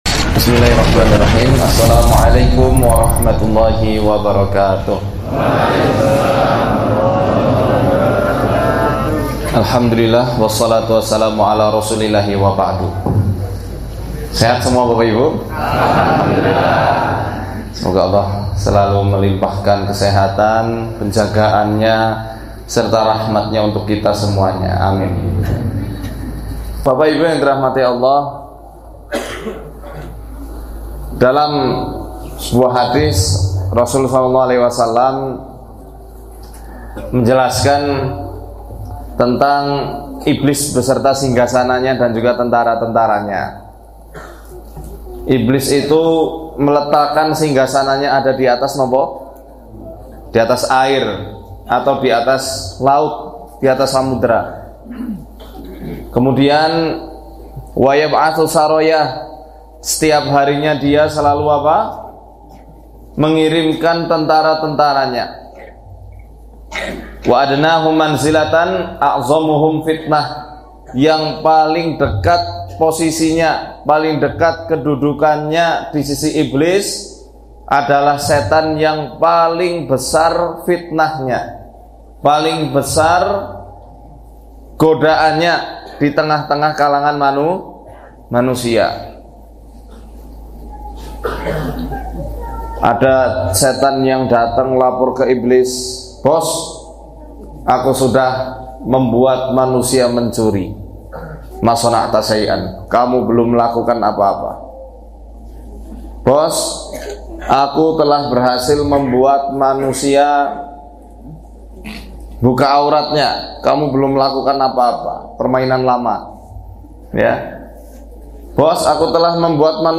Kajian ini membahas pentingnya menjaga rumah tangga dari gangguan makhluk gaib (jin dan setan) dengan cara-cara yang sesuai dengan tuntunan syariat Islam. Gangguan jin bukan hanya berbentuk kerasukan, tetapi juga bisa berupa gangguan halus seperti rasa malas beribadah, sering terjadi pertengkaran dalam rumah, hingga kesempitan rezeki.